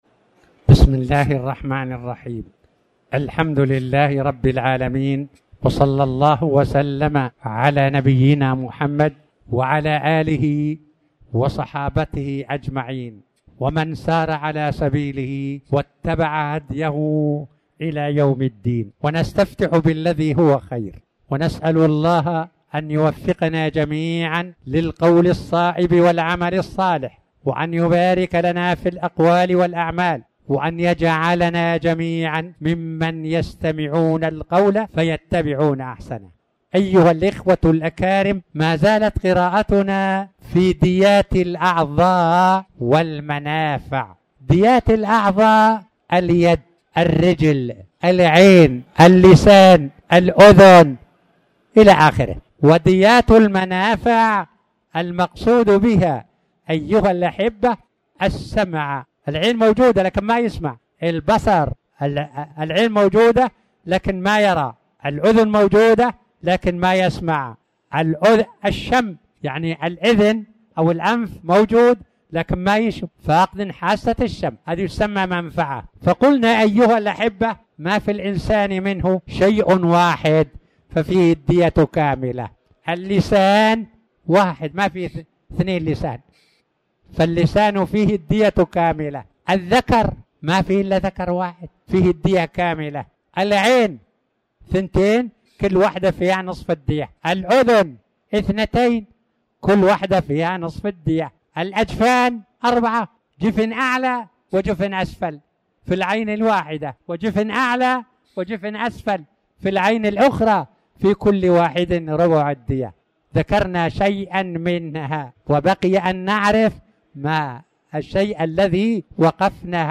تاريخ النشر ٧ محرم ١٤٤٠ هـ المكان: المسجد الحرام الشيخ